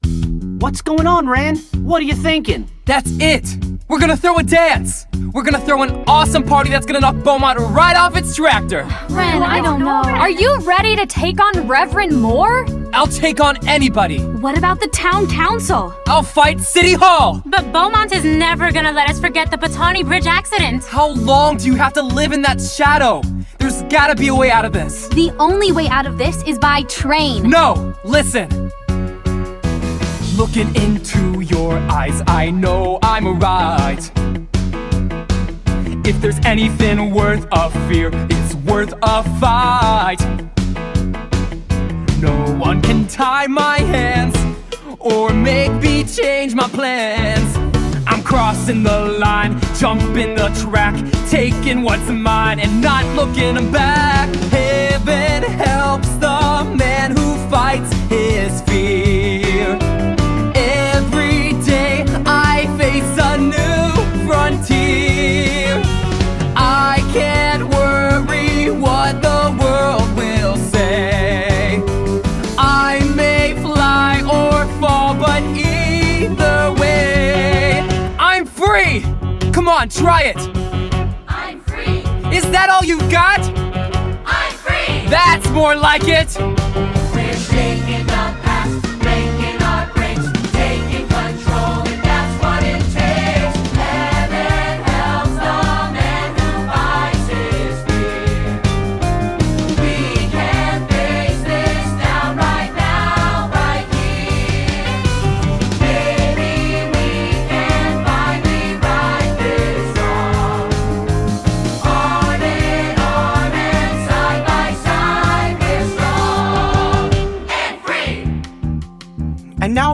Full Cast